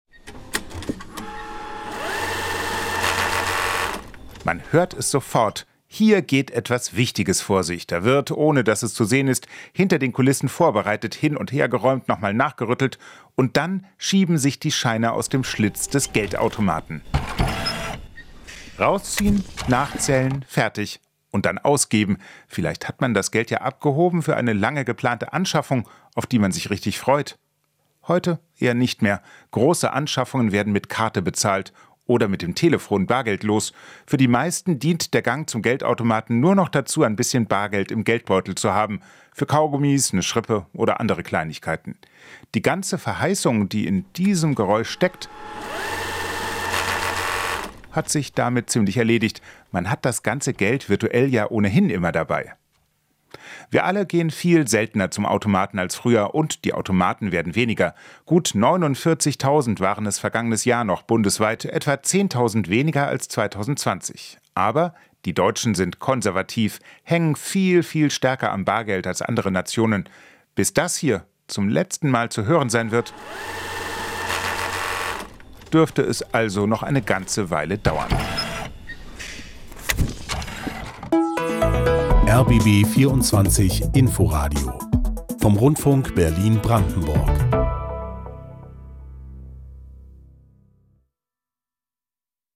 Geräusche, die verschwinden: Der Geldautomat